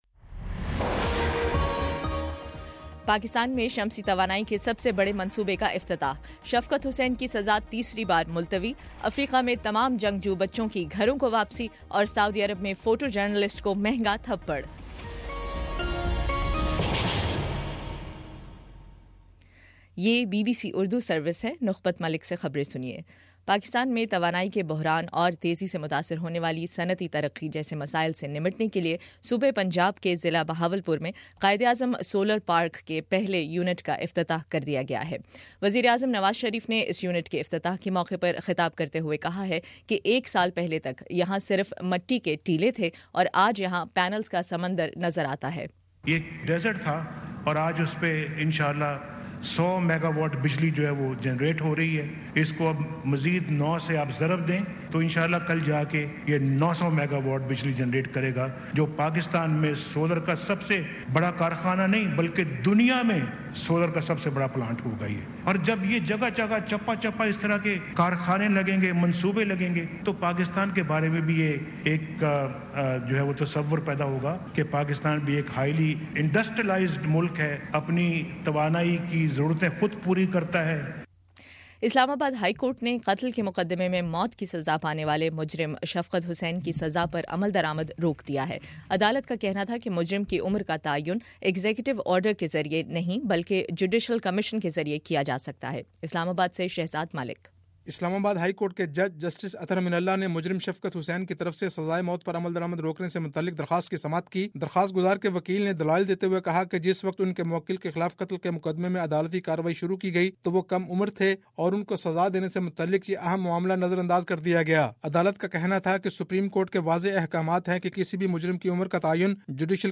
مئی05 : شام پانچ بجے کا نیوز بُلیٹن